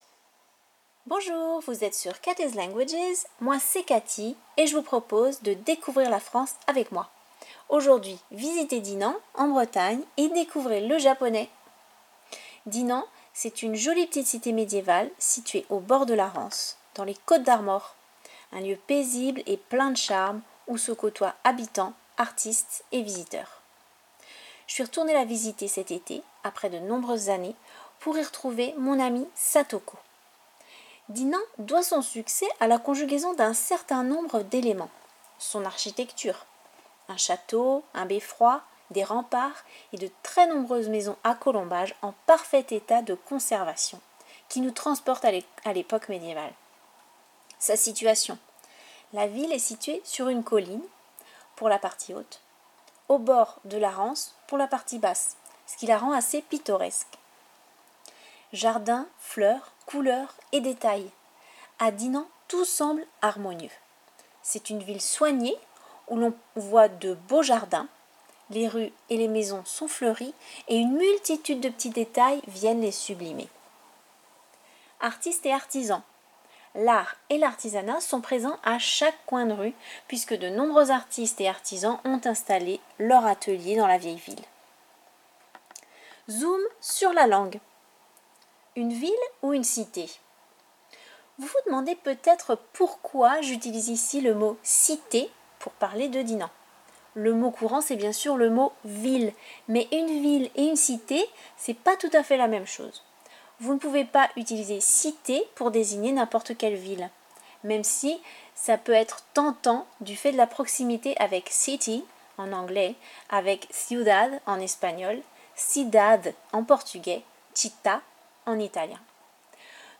Visitez Dinan (Bretagne) et découvrez le japonais: reportage et interview